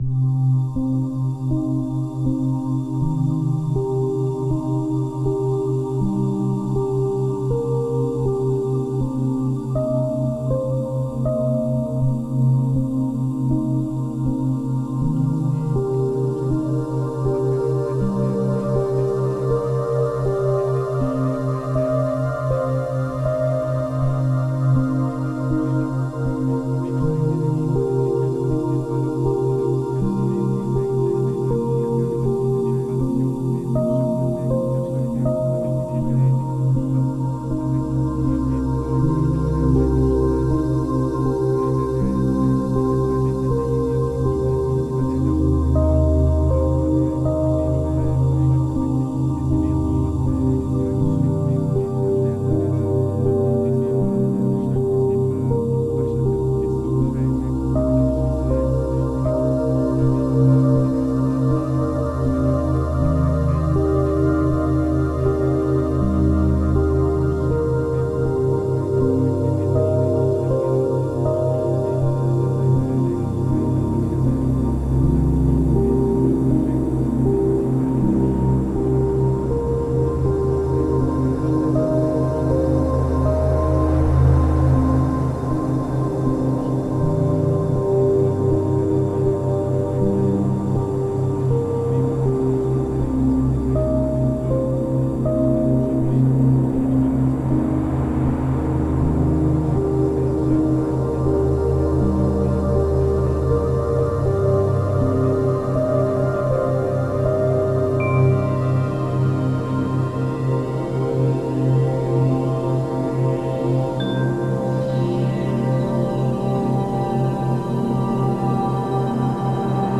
(Version RELAXANTE)
Alliage ingénieux de sons et fréquences curatives, très bénéfiques pour le cerveau.
Pures ondes thêta apaisantes 4Hz de qualité supérieure.
SAMPLE-Attraction-1-relaxant.mp3